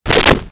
Whip 05
Whip 05.wav